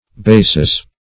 Basis \Ba"sis\ (b[=a]"s[i^]s), n.; pl. Bases (b[=a]"s[=e]z).